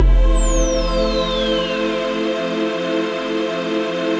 ATMOPAD25.wav